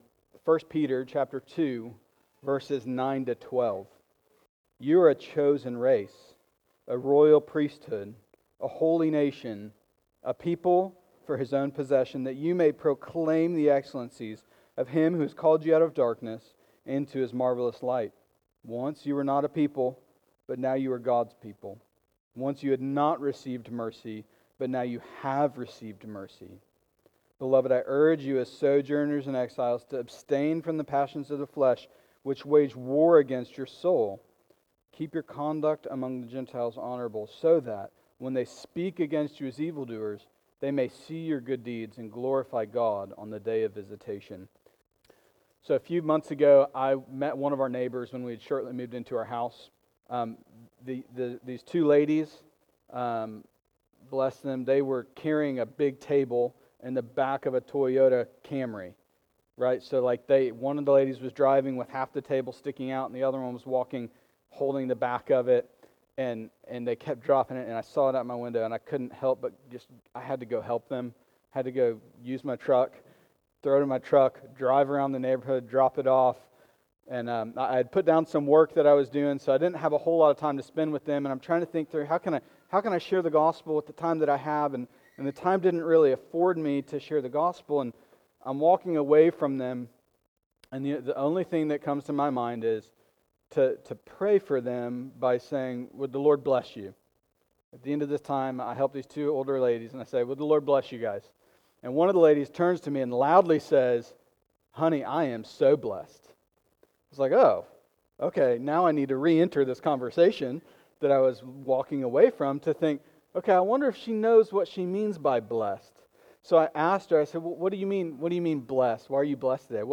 Sermons | Pillar Community Church